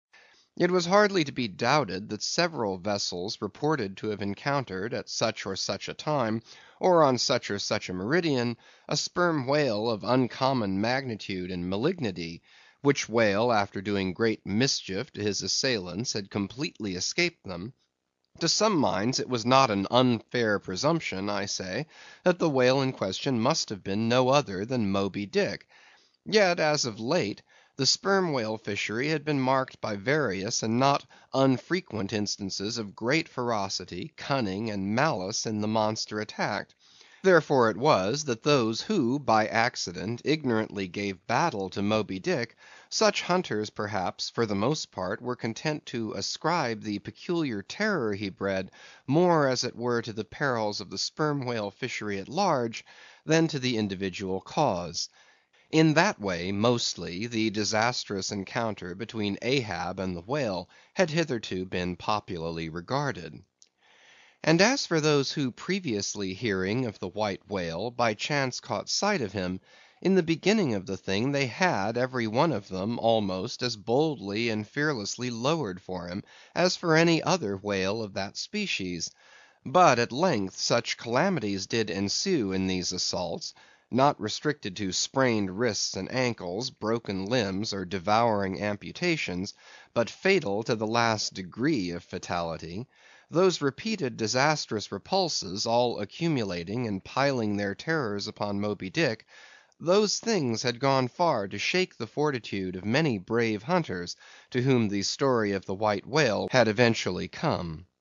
英语听书《白鲸记》第435期 听力文件下载—在线英语听力室